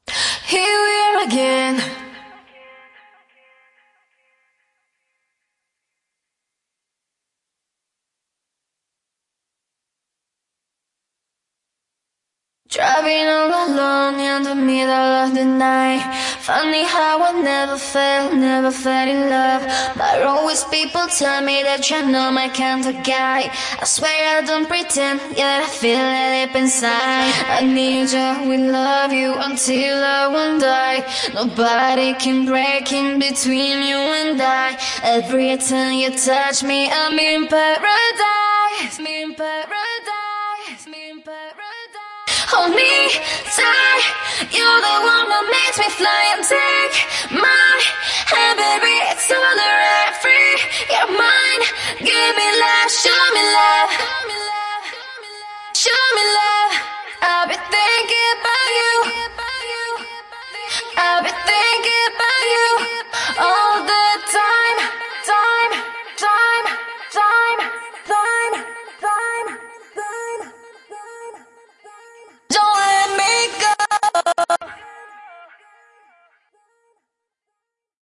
Acappella